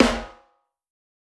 SNARE_ORDER.wav